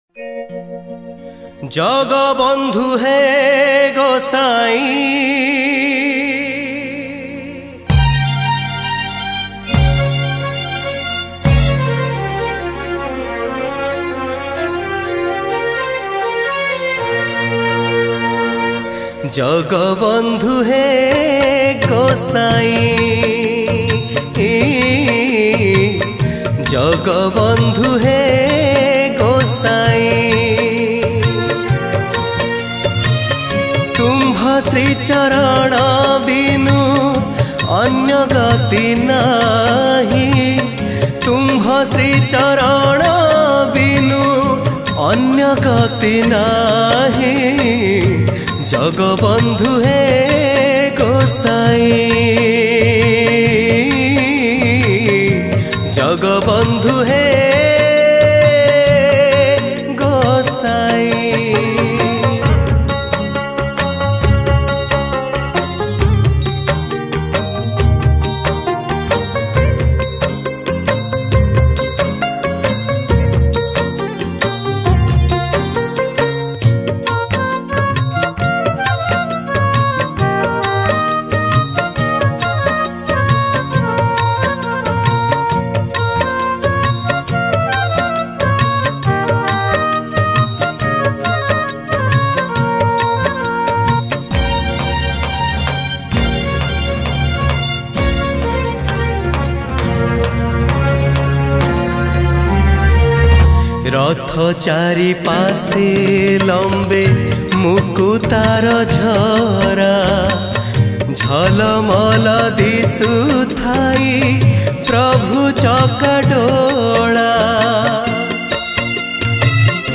Odia Devotional Song
Category: Odia Bhakti Hits Songs